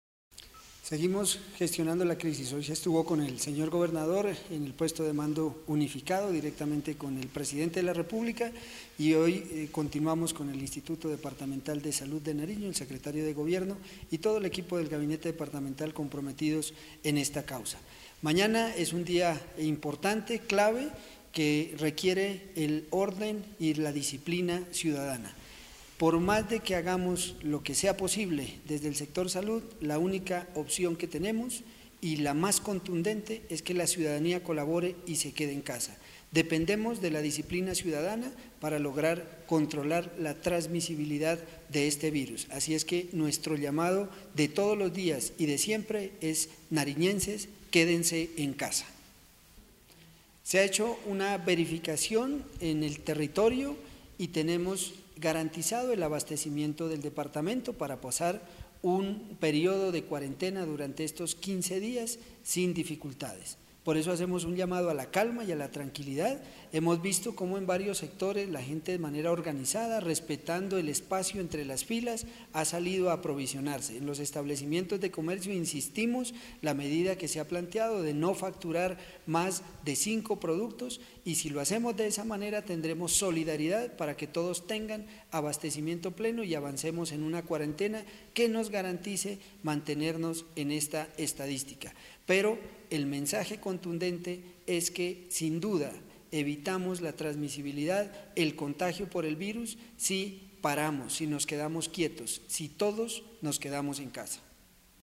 Por su parte, el Gerente de Atención del COVID -19, Mario Benavides, manifestó: “Este martes 24 de marzo es un día clave para proteger y contener la propagación del virus, por ello reitero el llamado a que las personas permanezcan en casa”. También, aseguró que con el trabajo interinstitucional se garantizará el abastecimiento de los productos en la región durante el periodo de aislamiento.
Mario-Benavides-Gerente-atencion-crisis-3.mp3